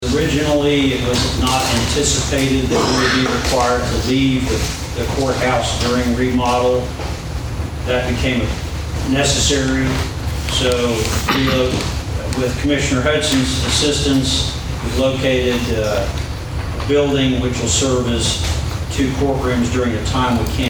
District Judge Stuart Tate talks about what steps have been taken.